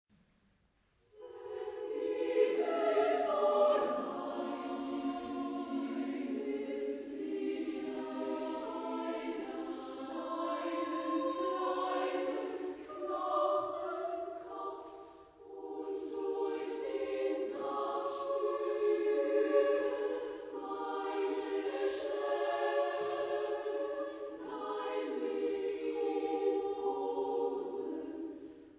Zeitepoche: 20. Jh.
Chorgattung: SSAAA